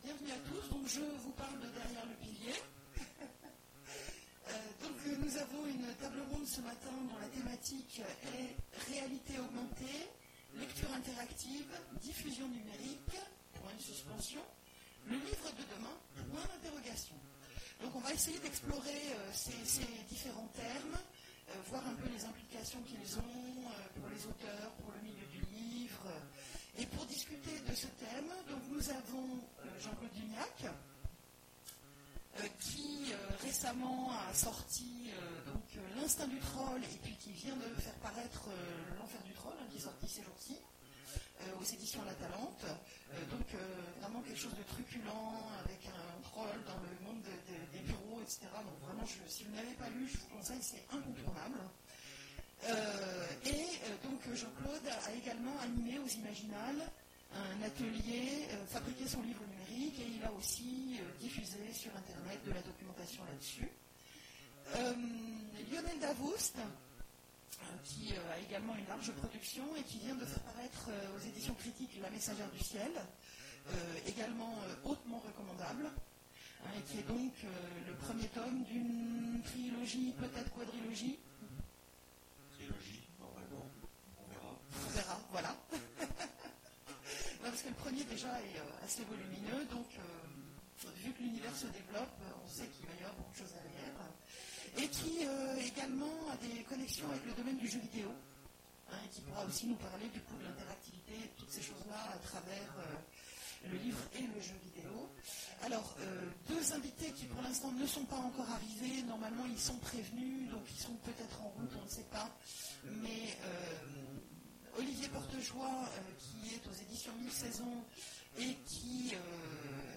Imaginales 2017 : Conférence Réalités augmentées, lectures interactives, diffusion numérique…